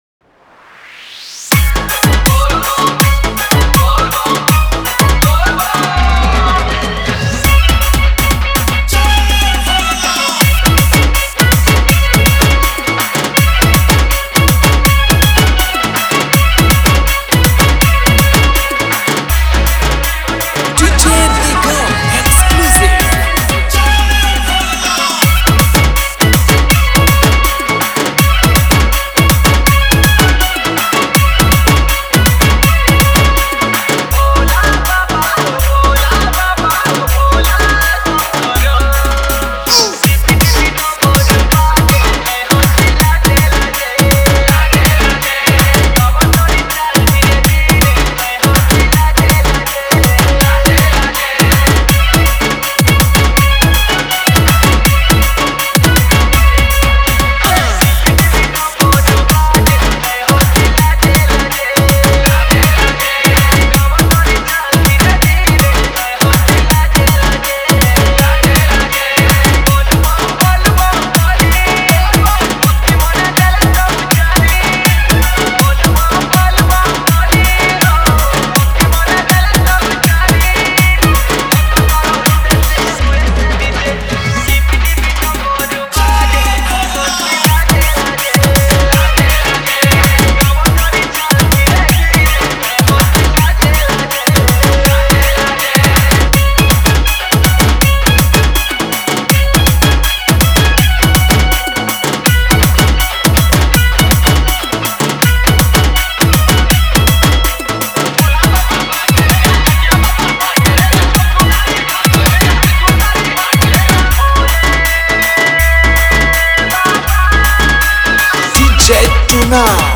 Bolbum Special Dj Song Songs Download
Bhajan Dj Remix